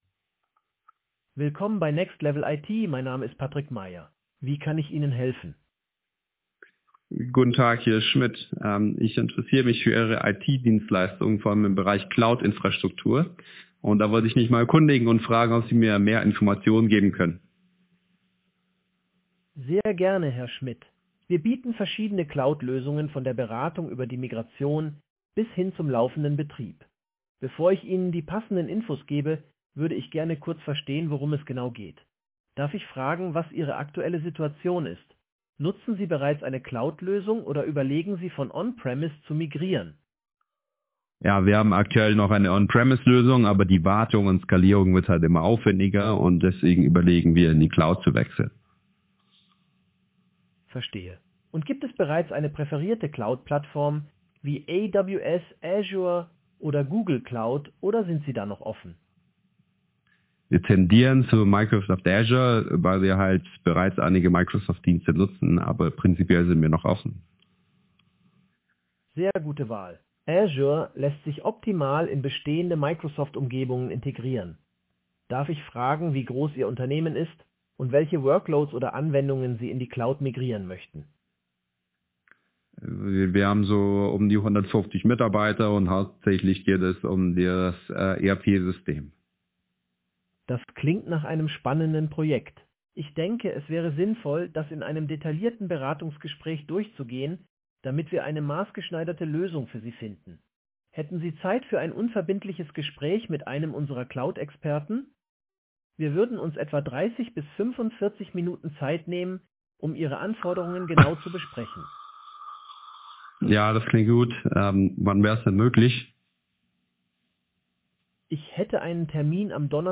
Here is a German-language audio sample of a phone call with a customer who is interested in IT services and would like more information about cloud infrastructure. The AI answers various questions, asks follow-up questions and responds to the caller, almost like a human conversation partner.
Voice AI audio sample: customer conversation
hoerbeispiel-kundengespraech.mp3